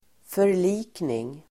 Uttal: [för_l'i:kning]